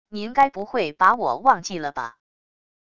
您该不会把我忘记了吧wav音频生成系统WAV Audio Player